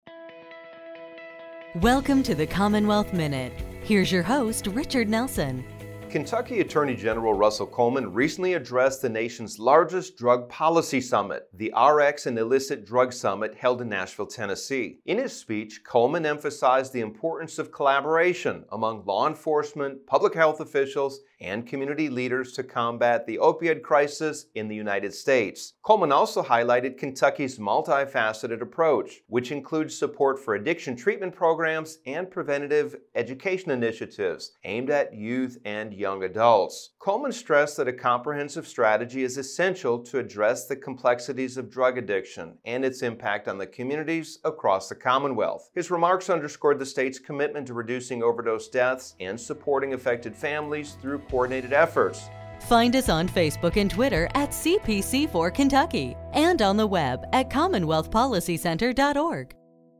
AG Coleman Keynote’s Speech on Illicit Drugs